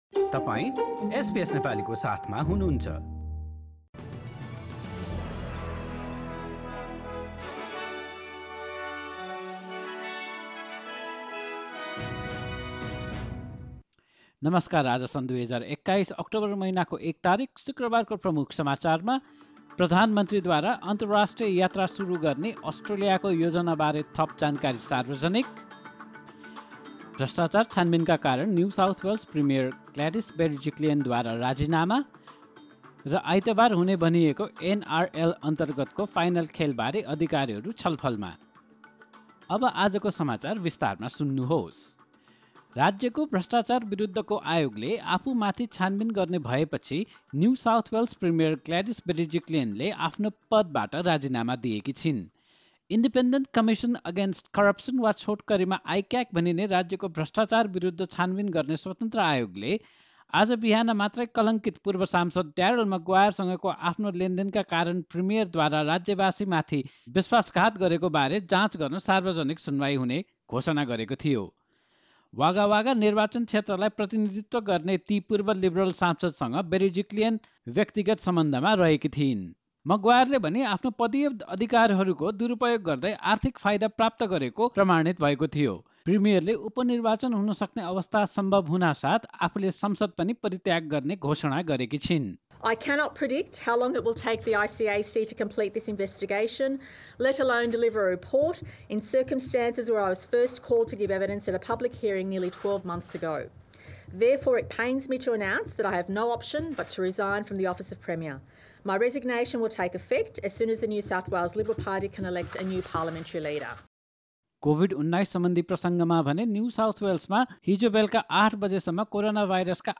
एसबीएस नेपाली अस्ट्रेलिया समाचार: शुक्रवार १ अक्टोबर २०२१